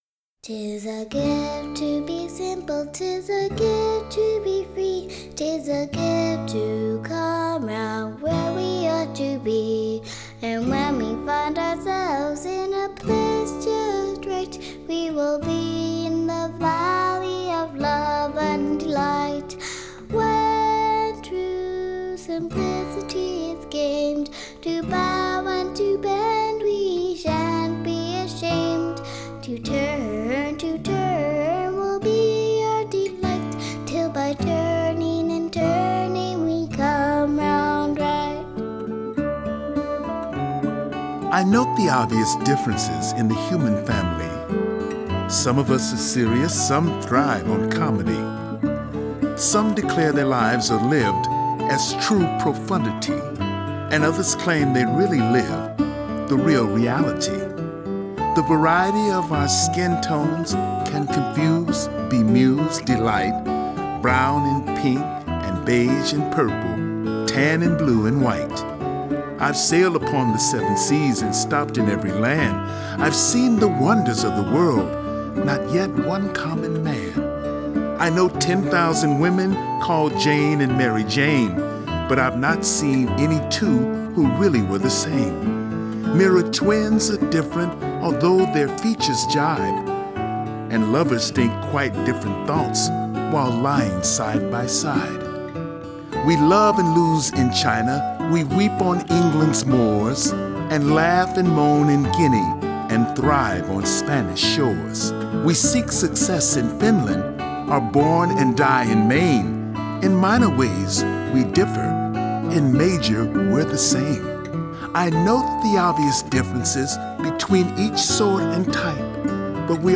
January's track was Maya Angelou’s poem “Human Family”, recited by Danny Glover and accompanied by the Shaker song “Simple Gifts” by Elder Joseph Brackett.